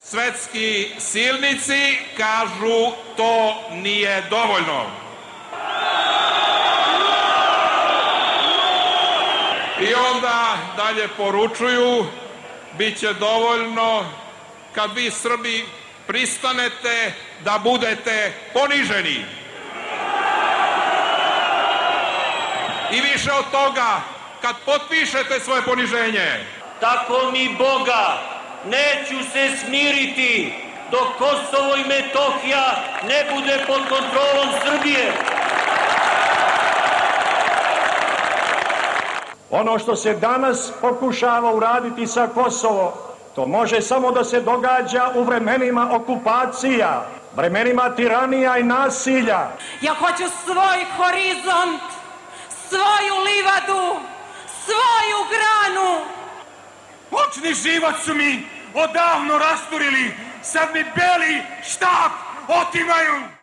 Ton sa mitinga 2008. kada je zapaljena ambasada SAD u Beogradu